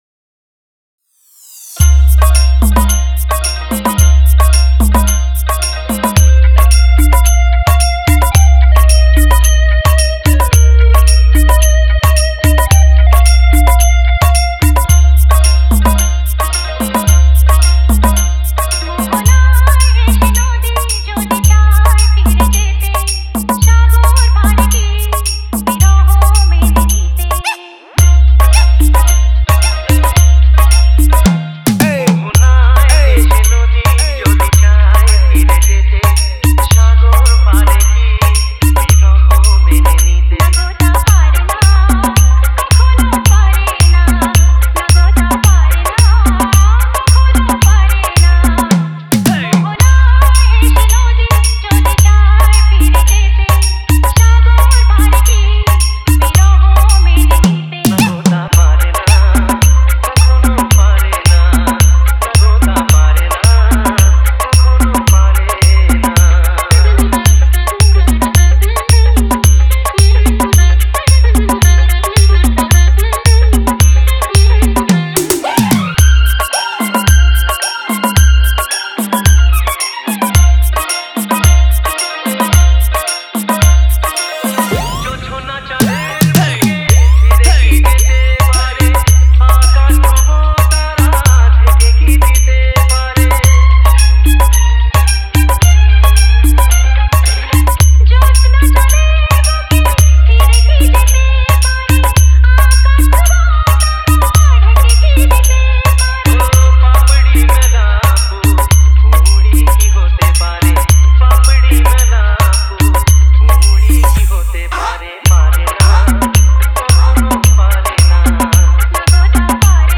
Bengali Humbing Dance Mix